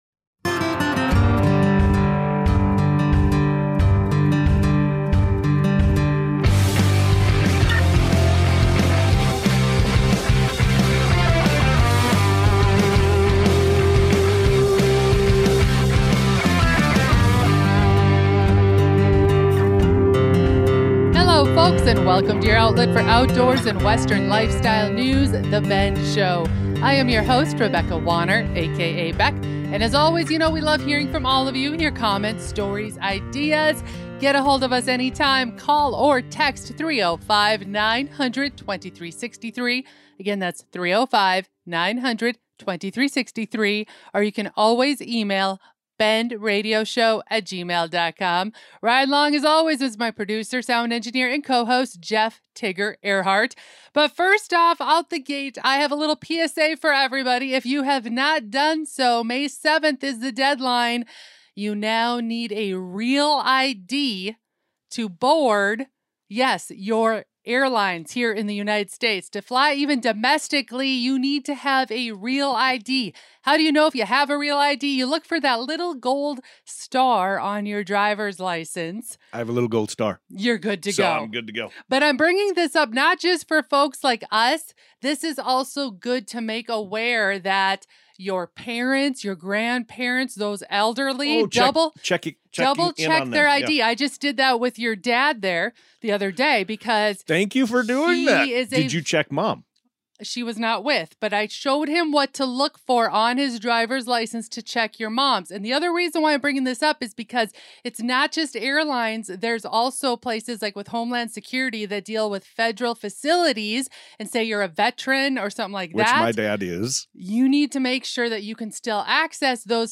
The Bend Radio Show & Podcast Episode 235: Why Last-Minute Road Trips Are the Best Way to Travel (Especially in the Off-Season)